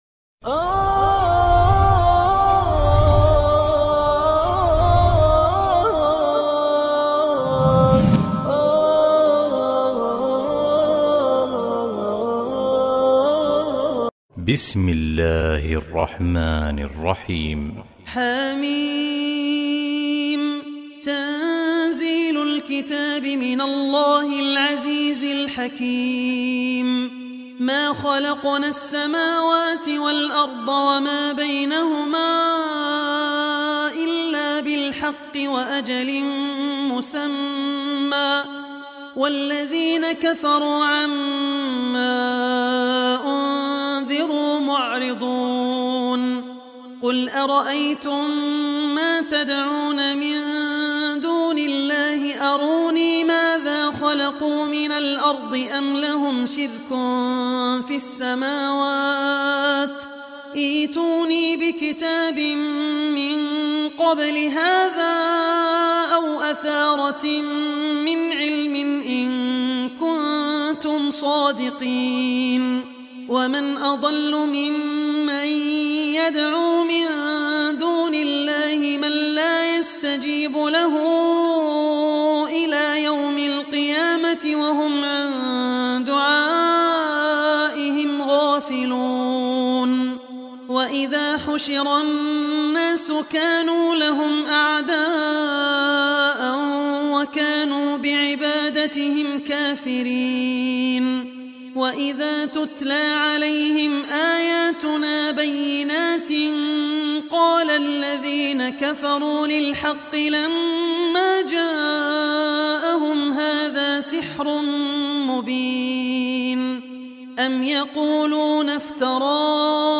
Surah Al-Ahqaf Recitation by Abdur Rahman Al Ossi
Surah Al-Ahqaf is 46 chapter of Holy Quran. Listen online mp3 tilawat / recitation of Surah Al-Ahqaf in Arabic in the voice of Abdur Rahman Al Ossi.